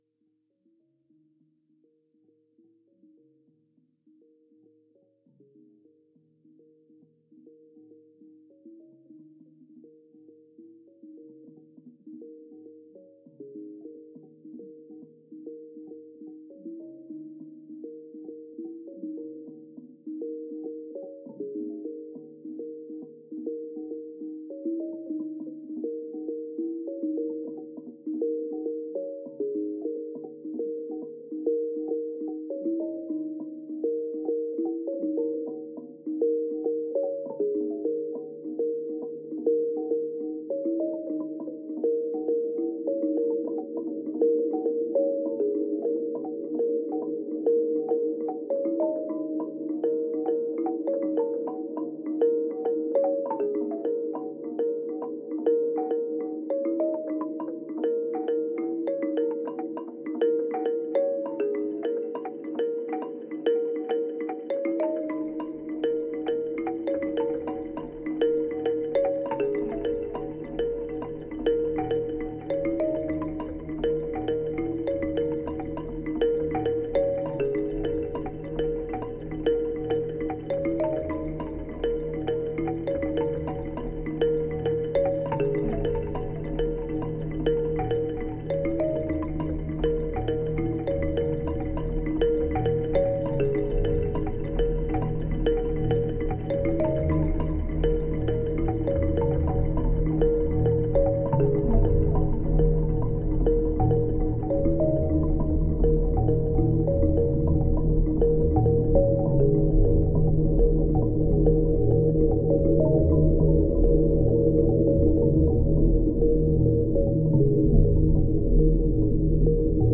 Live at Zirkonia Fabrik (Berlin, 1st of May 2012)